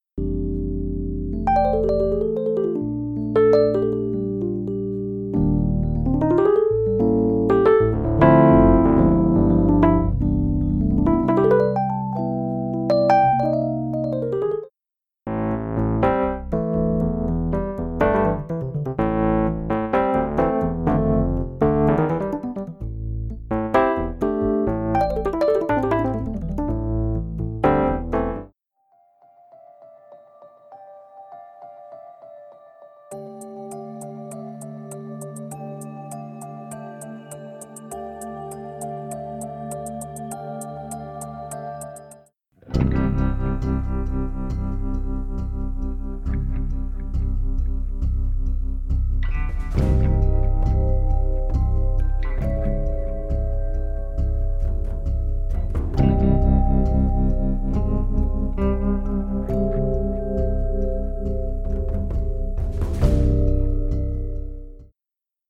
扩展了经典电钢琴的能力，AVANT提供了各种华丽，创新和独特的声音供您探索。
经典电钢琴
这个标志性乐器的内部组件被隔离，并通过使用锤子，镣铐，麻绳和镐头的原始准备表演来捕捉。
- 回声功能为表演增添了郁郁葱葱的音乐随机性
- 可控的踏板，键和机械噪声以增强真实感